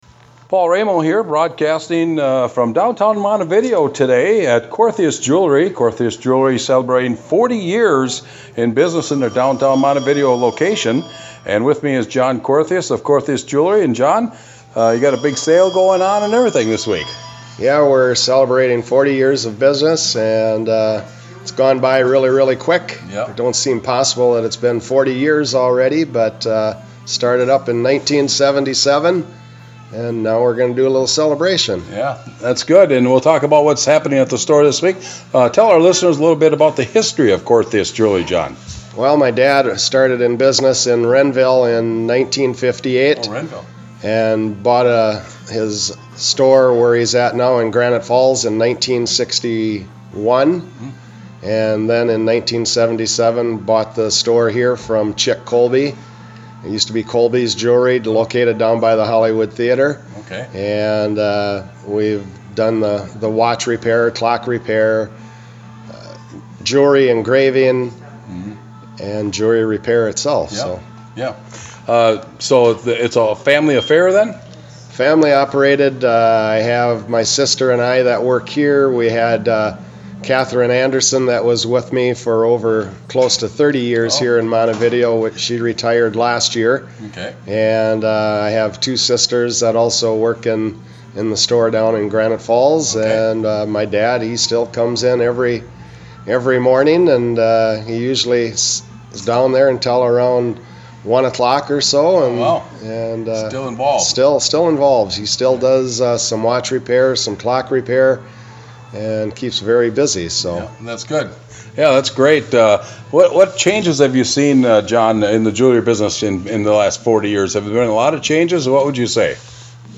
Under: Interviews, Programming